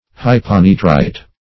Hyponitrite \Hy`po*ni"trite\, n.
hyponitrite.mp3